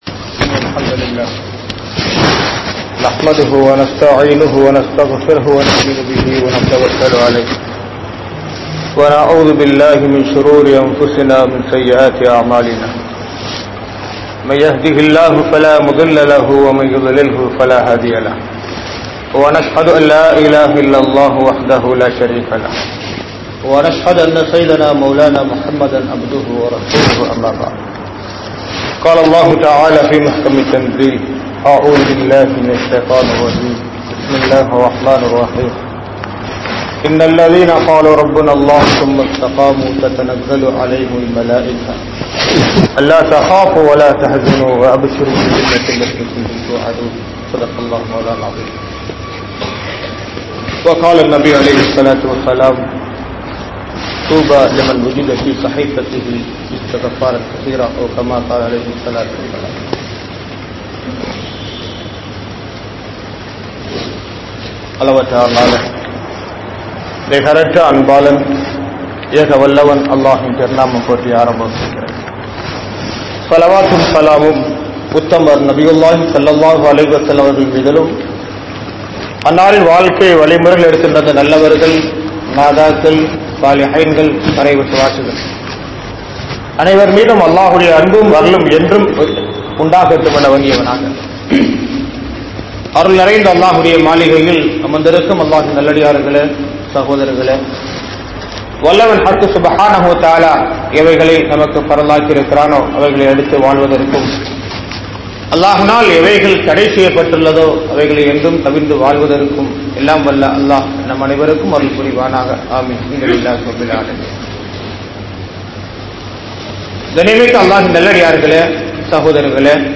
Sahbaan Maathathin Sirappuhal (ஷஃபான் மாதத்தின் சிறப்புகள்) | Audio Bayans | All Ceylon Muslim Youth Community | Addalaichenai